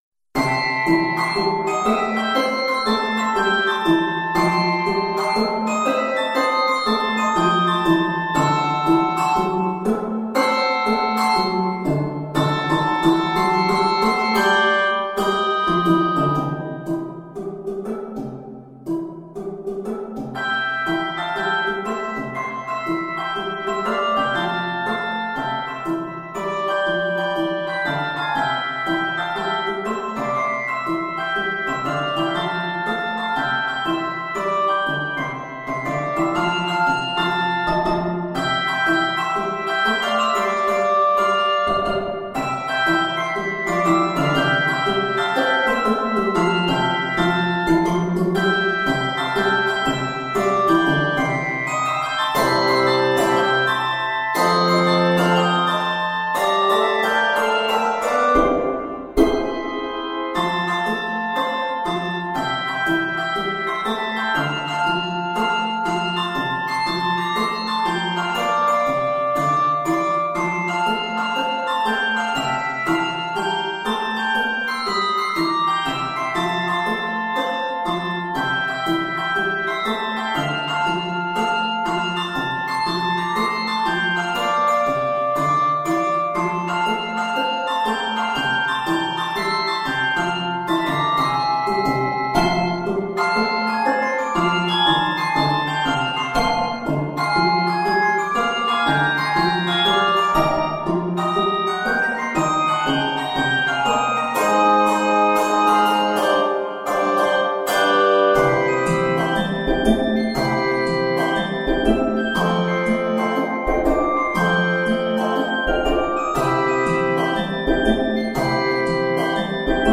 with a boogie bass line and a treble line with a swing